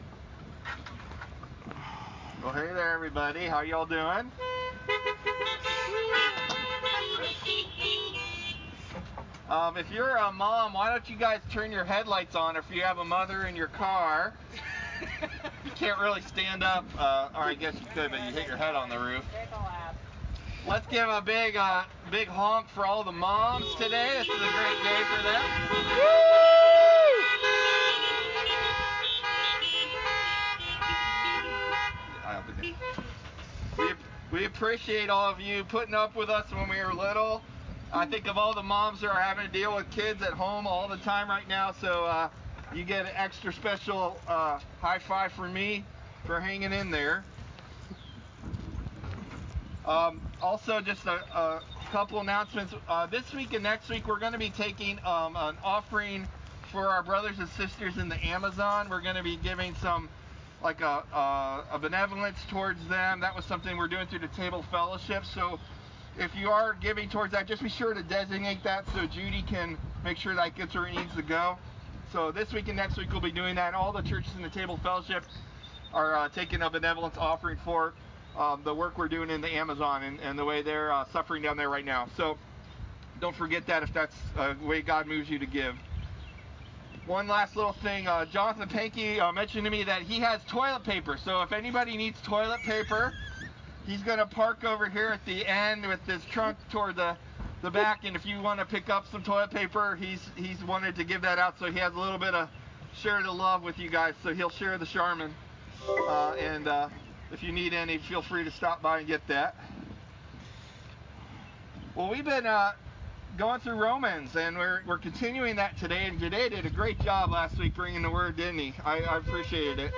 Our video message was recorded on Facebook Live.